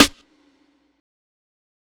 Metro Hall Snare.wav